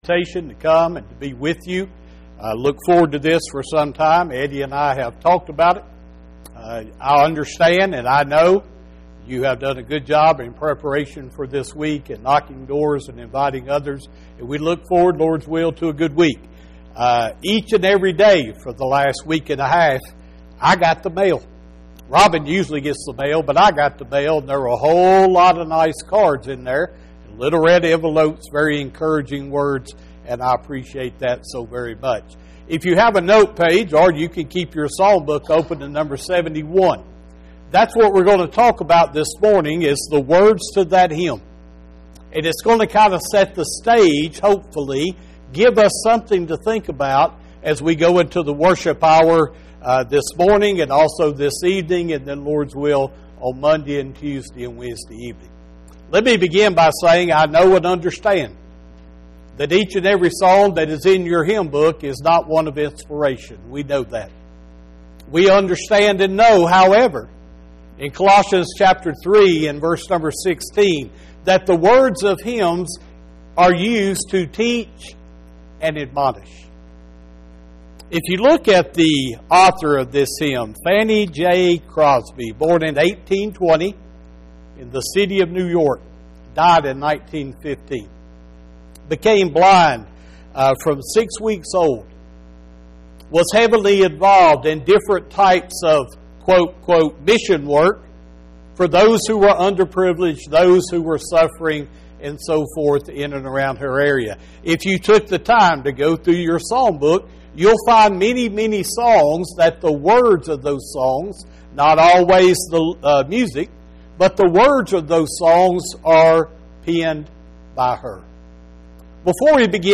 Blessed Assurance – Gospel Meeting